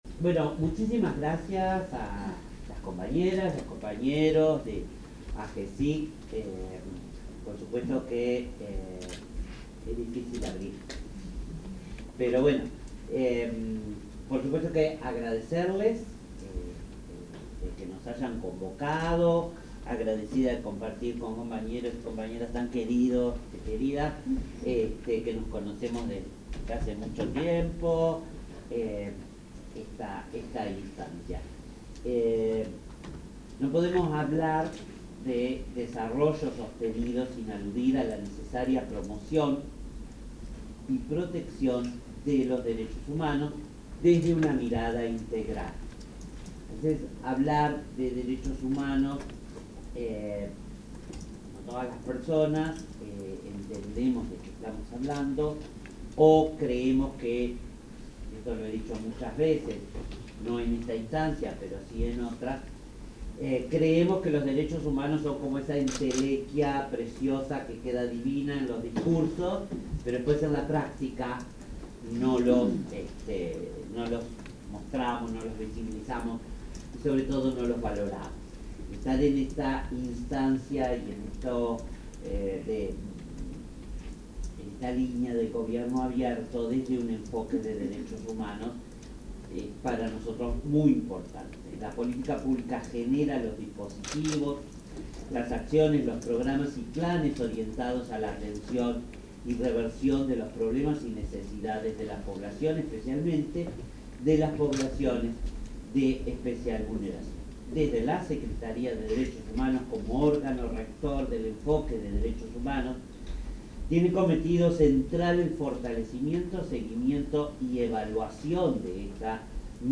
Declaraciones de autoridades en mesa de diálogo sobre Gobierno Abierto
Declaraciones de autoridades en mesa de diálogo sobre Gobierno Abierto 03/06/2025 Compartir Facebook X Copiar enlace WhatsApp LinkedIn En el marco de la construcción del Sexto Plan de Acción Nacional de Gobierno Abierto, se realizó la Tercera Mesa de Diálogo: Derechos Humanos y Desarrollo Sostenible. En la apertura, se expresaron la secretaria de Derechos Humanos, Collette Spinetti, y los subsecretarios de Vivienda y Ordenamiento Territorial, Christian Di Candia, e Interior, Gabriela Valverde.